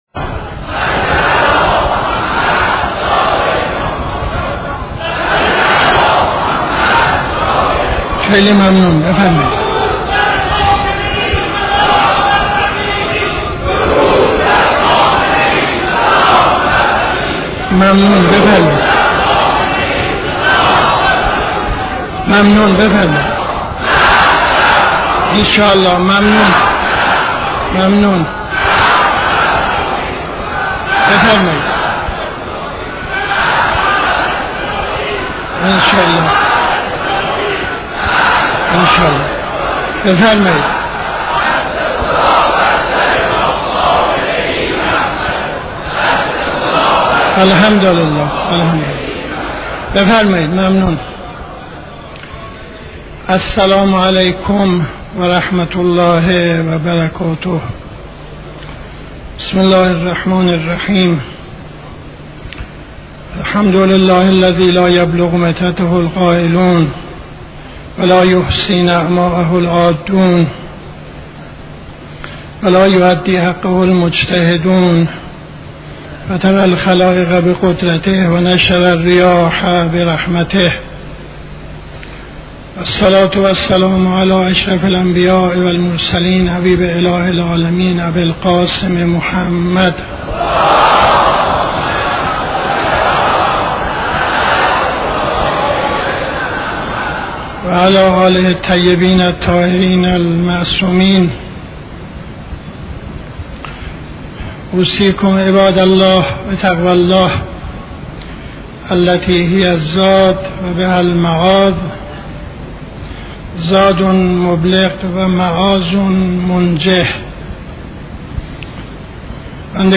خطبه اول نماز جمعه 12-03-74